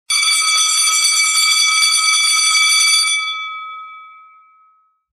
Old-Fashioned Fire Alarm Sound Effect
A classic, vintage-style fire alarm sound with a loud and urgent ringing tone.
Old-fashioned-fire-alarm-sound-effect.mp3